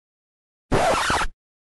Scratch Sound Zwj Botón de Sonido